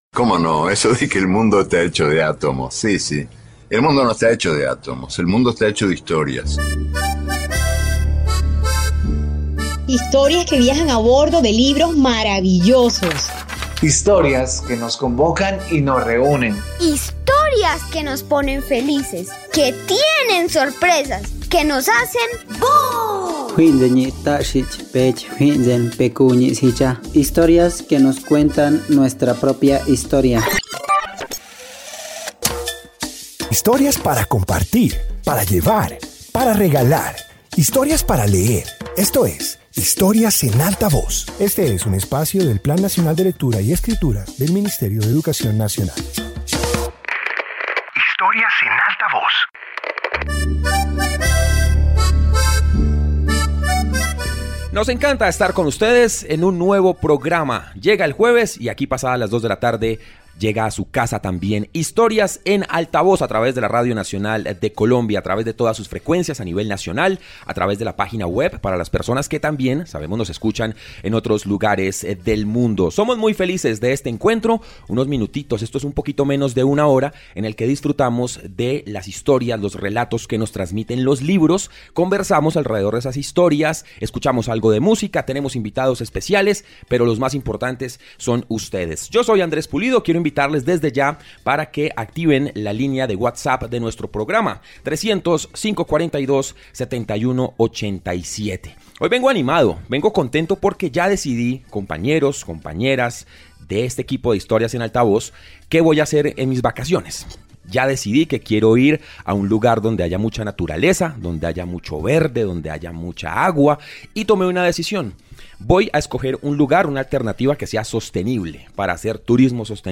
Introducción Este episodio de radio presenta historias que invitan a reflexionar sobre el cuidado de la Tierra. Incluye relatos sobre ecosistemas, especies y la importancia de proteger el entorno natural.